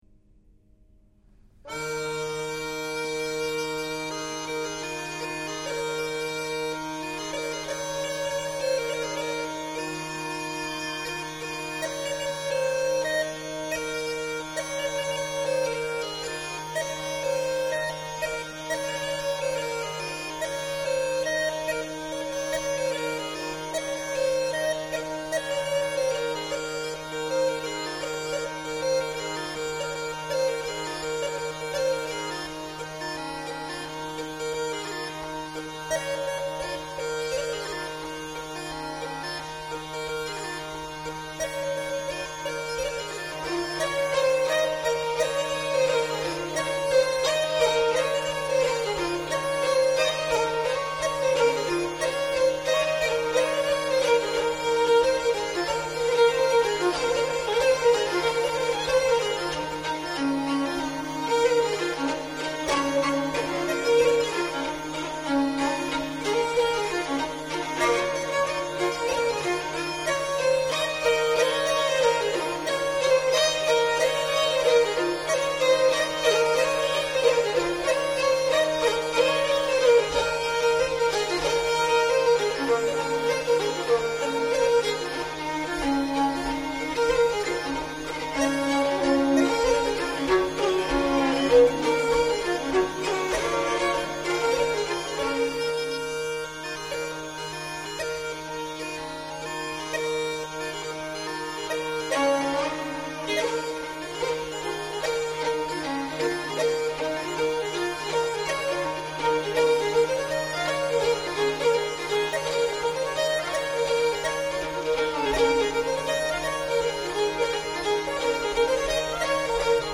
Gælic songs and dances of the 17th & 18th centuries
(march)